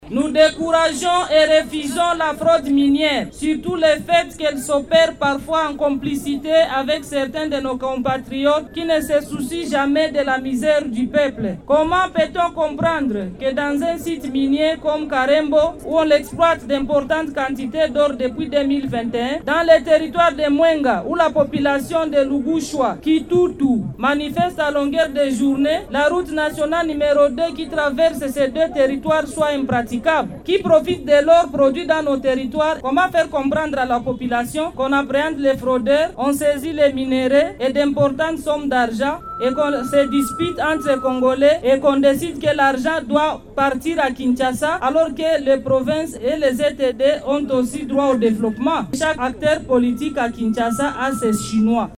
Ces forces vives de la société civile l’ont fait savoir au terme d’une marche pacifique organisée à Bukavu le mercredi 08 janvier 2025.